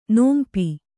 ♪ nōmpi